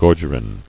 [gor·ger·in]